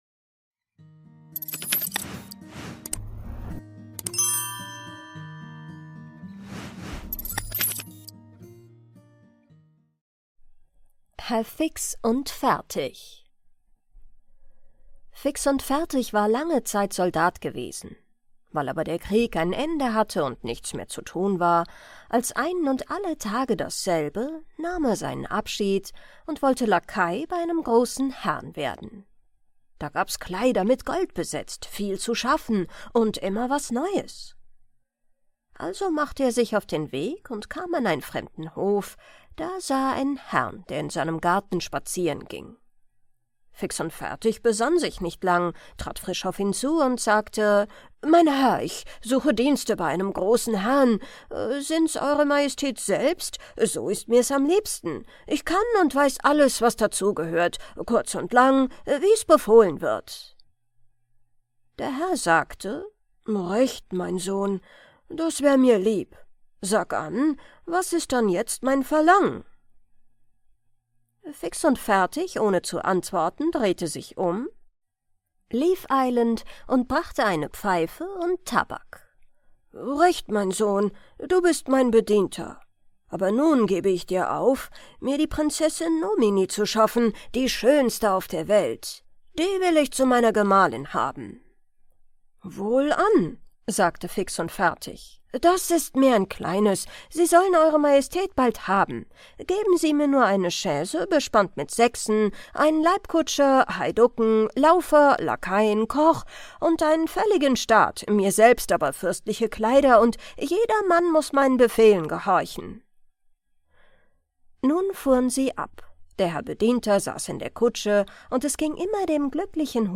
liest wie eine Schlaf-Therapeutin